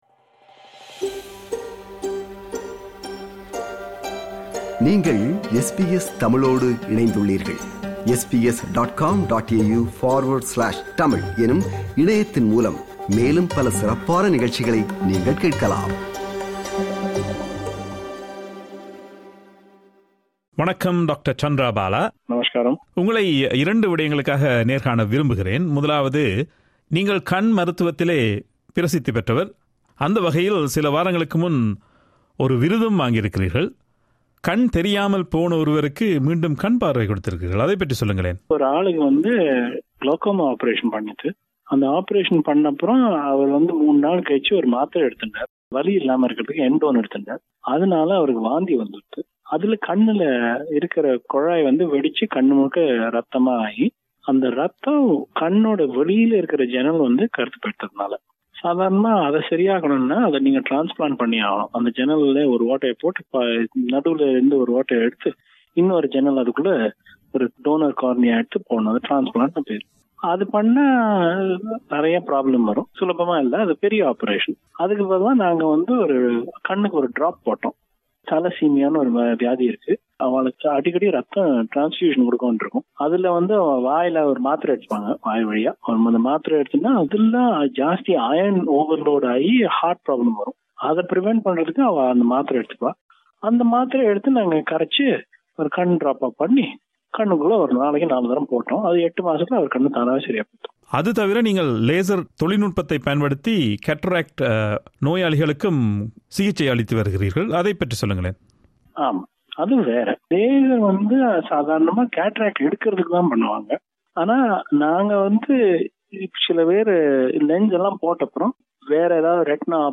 அந்த நேர்காணலின் மறு ஒலிபரப்பு இது.